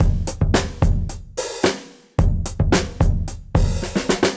Index of /musicradar/dusty-funk-samples/Beats/110bpm
DF_BeatA_110-03.wav